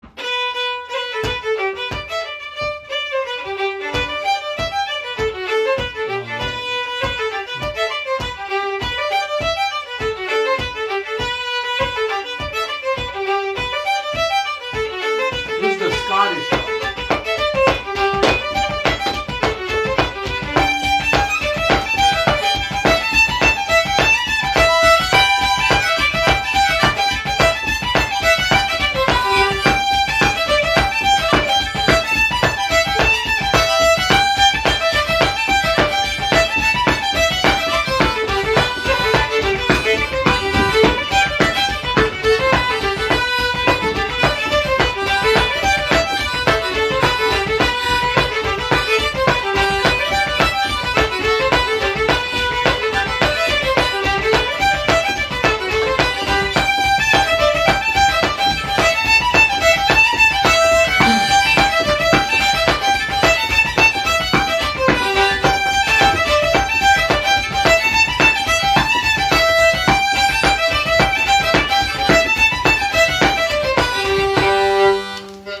Sessions are open to all instruments and levels, but generally focus on the melody.
New Brunswick Acadian version of a Scottish tune
Composer Dan R. MacDonald Type Reel Key G Recordings